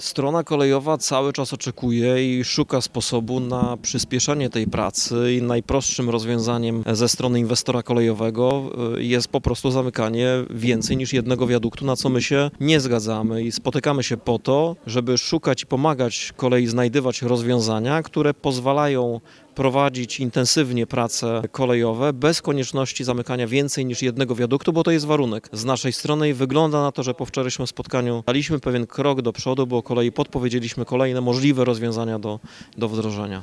Mówi Prezydent Rafał Zając.